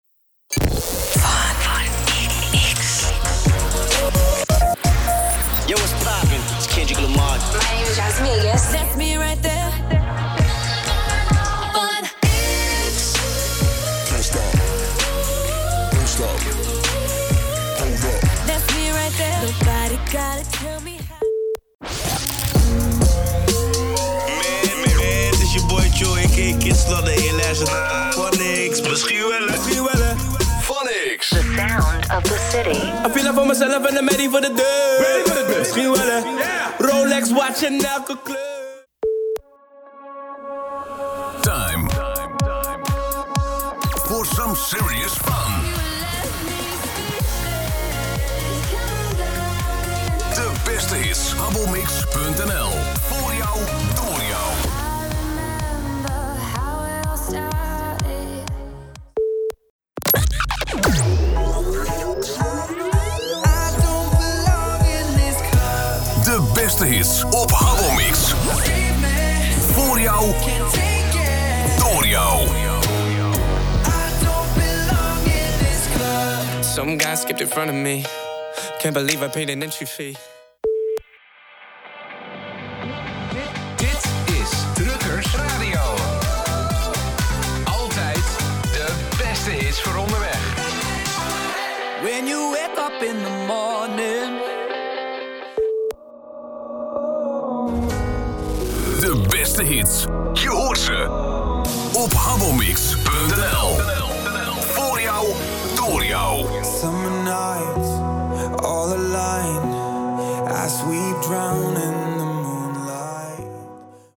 Demo Power Intro’s